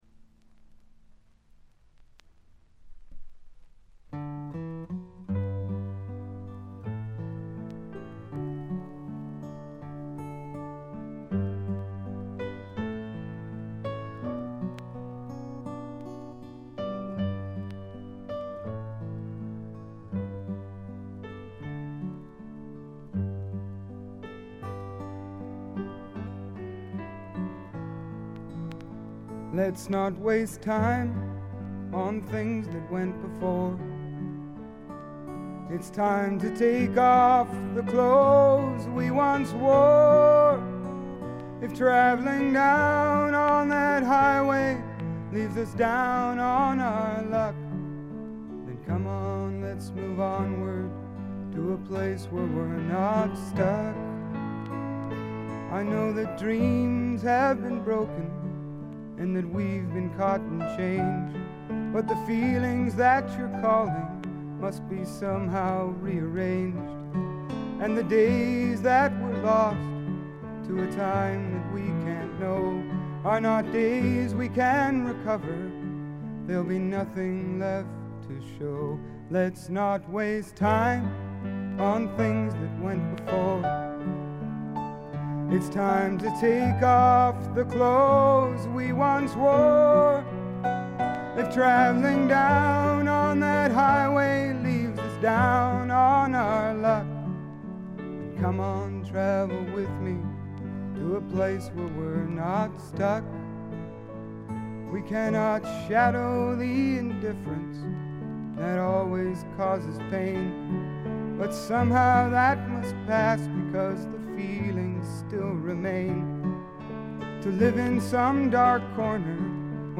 ところどころでチリプチ。
曲が素晴らしくよくできていてバックの演奏もシンプルで実に的確。
試聴曲は現品からの取り込み音源です。
Vocals, Acoustic Guitar